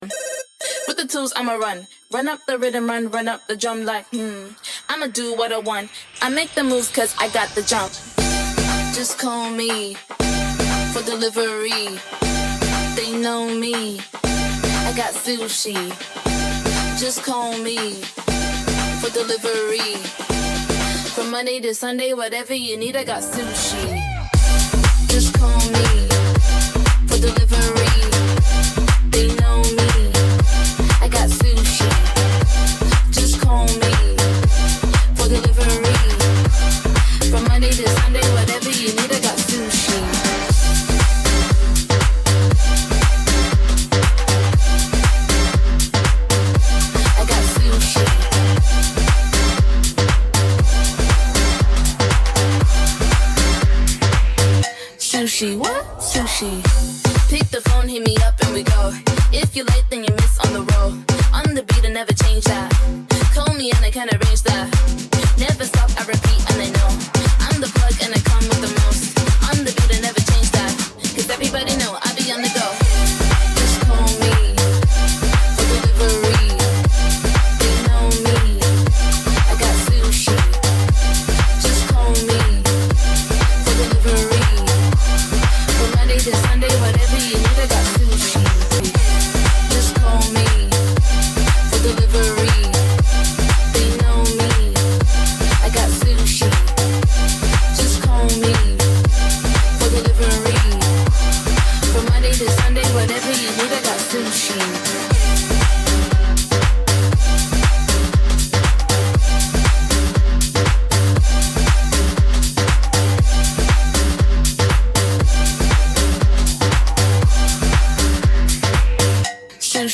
Special Edit 118 BPM